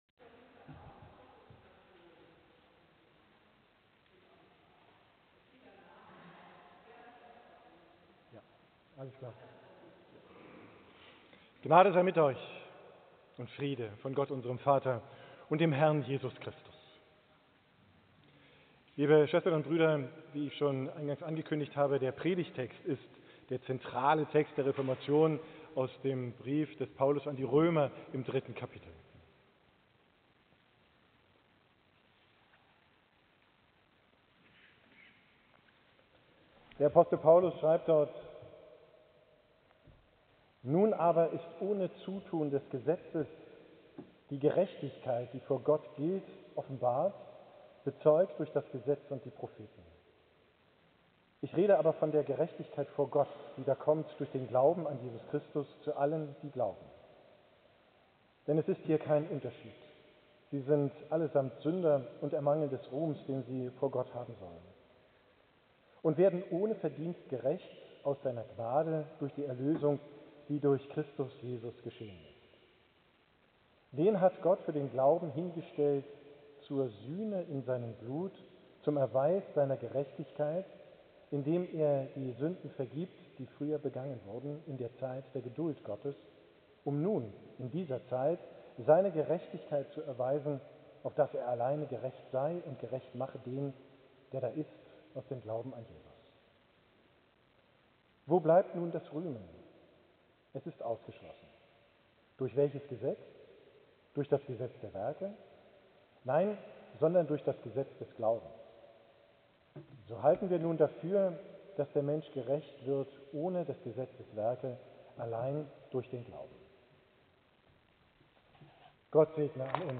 Predigt vom Reformationstag, 31.